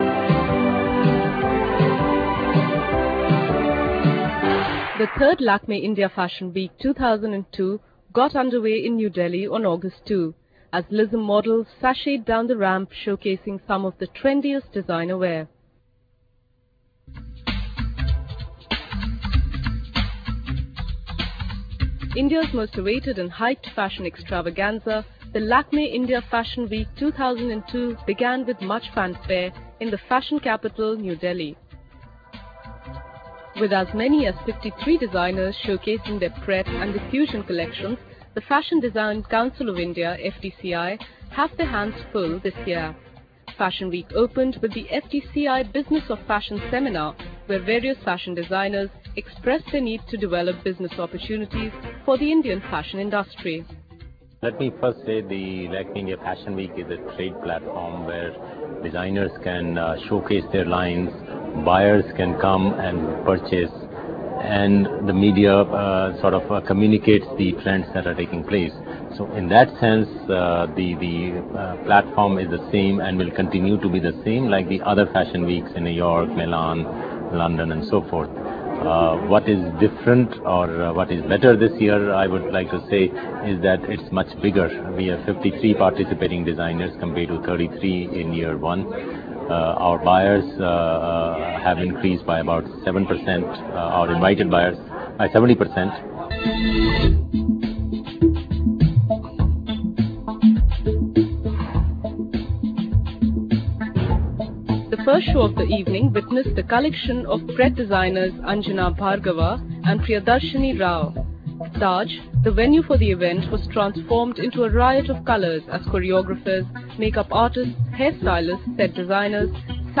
ON THE RAMP-AGE: Models sport colourful outfits at a fashion show of Lakme India Fashion Week 2002 in the Capital on Friday.